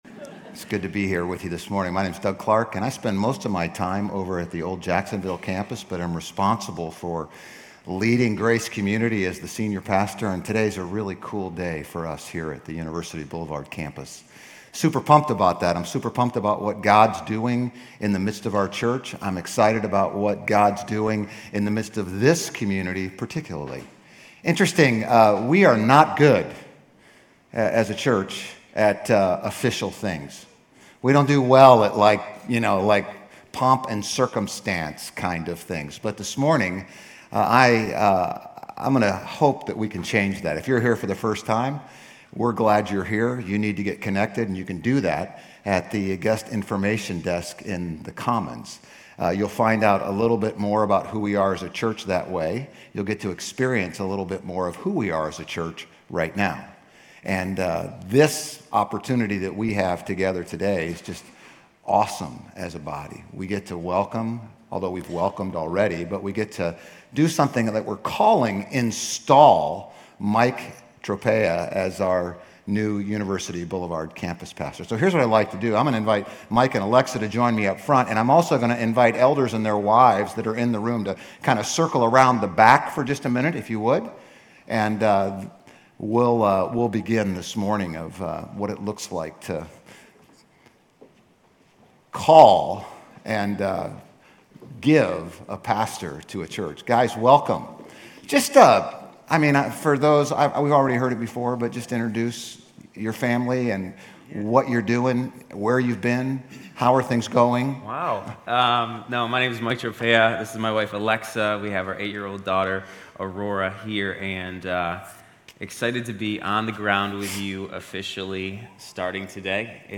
Grace Community Church University Blvd Campus Sermons